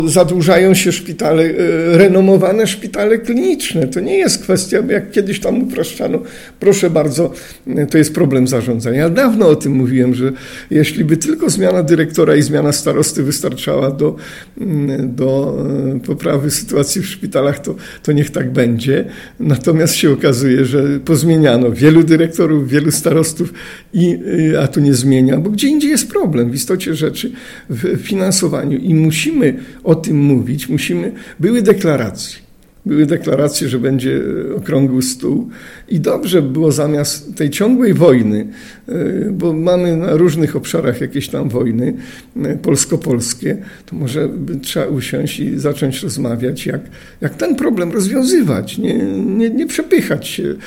WYSŁUCHAJ całej rozmowy z Józefem Matysiakiem, starostą powiatu rawskiego TUTAJ>>>